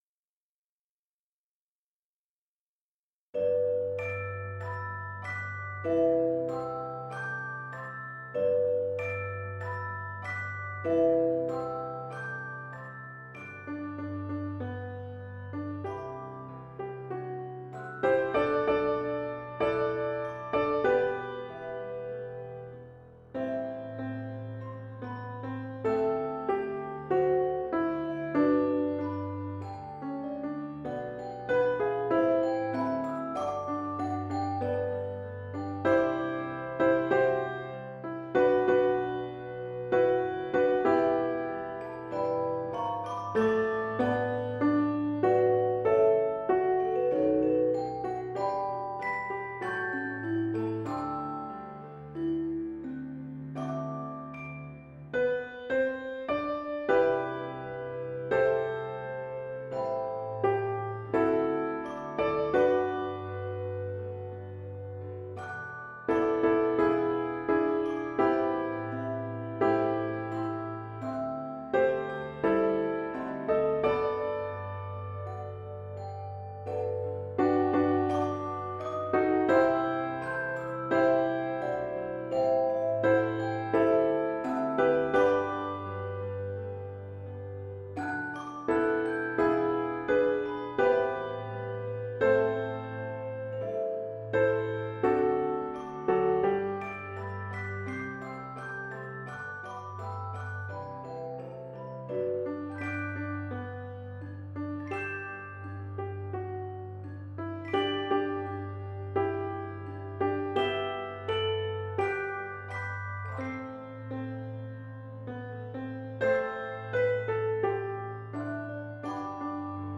Chorus Audio